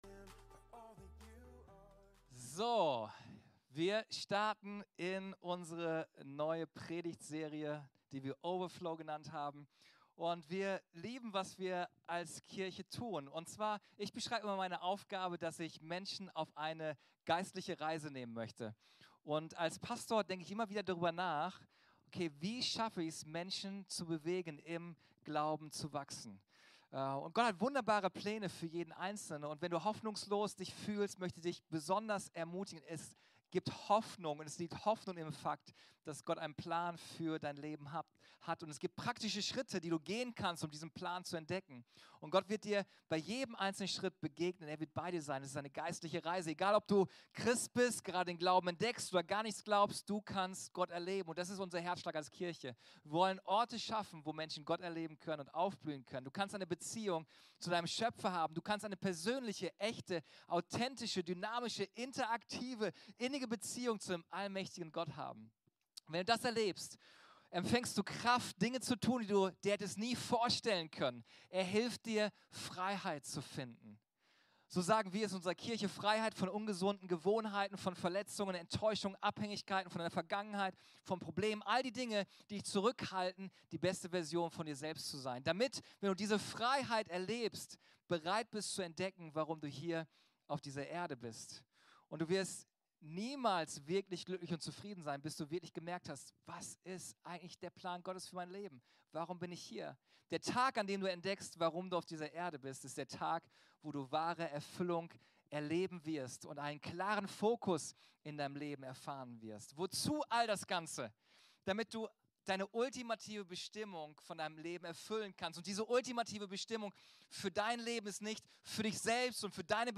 Unsere Predigt vom 5.11.23 Folge direkt herunterladen